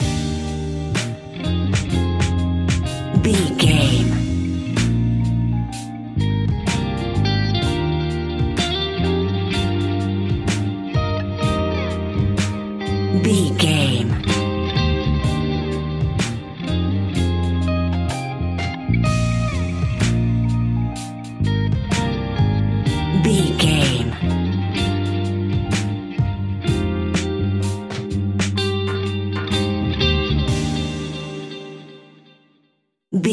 Ionian/Major
D♯
laid back
Lounge
sparse
new age
chilled electronica
ambient
atmospheric